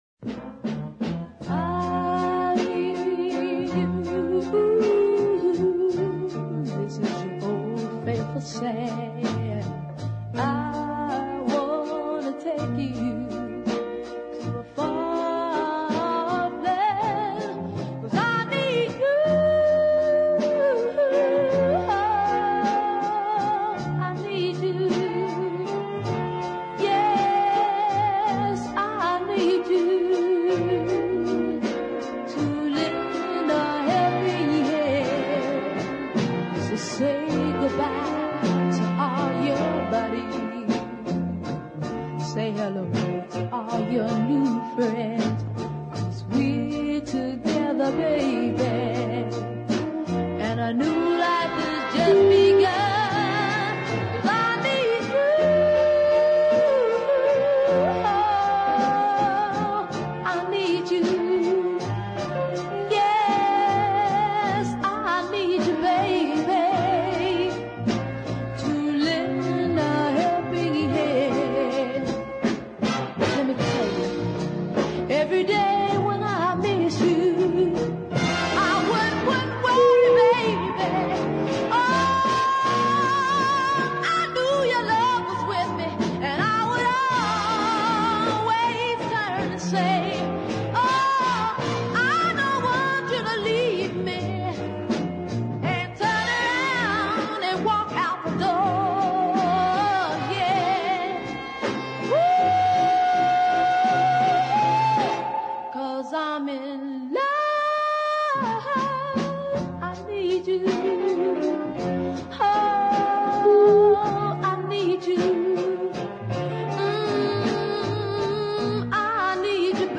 a lovely deep ballad of impeccable quality